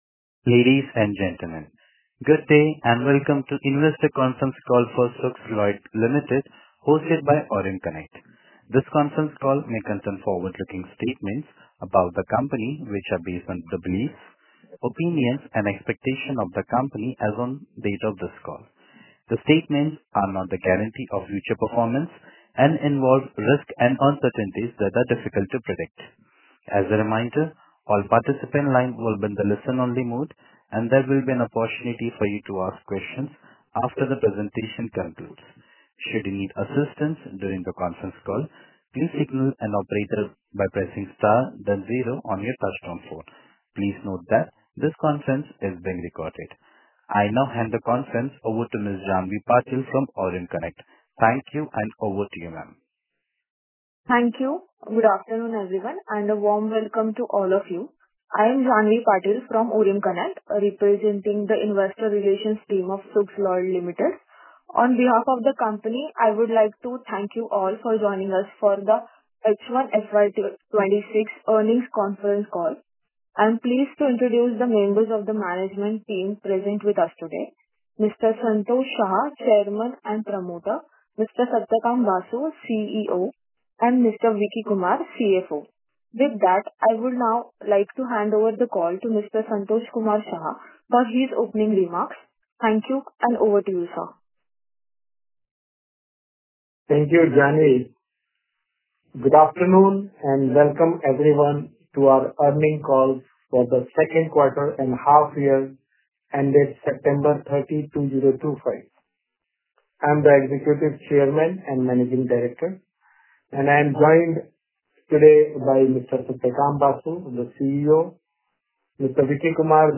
H1 FY26 Concall Audio Recording.mp3